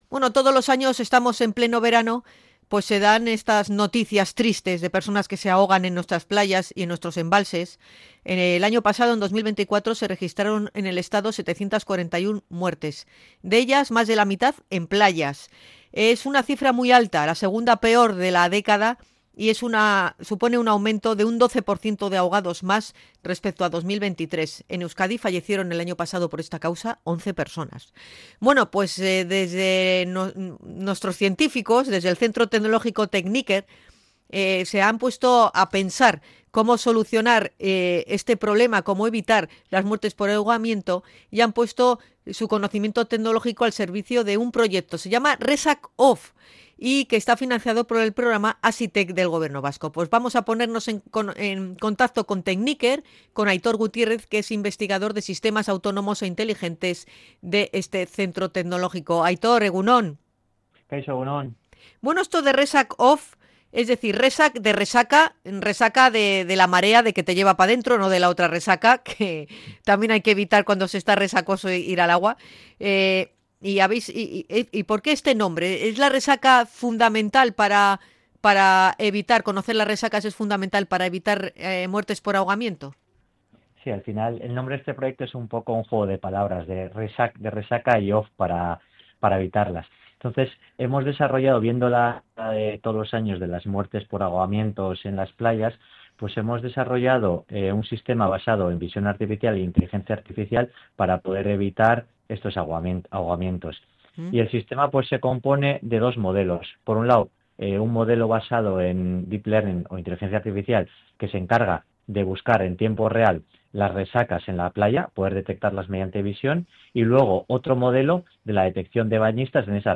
Podcast Tecnología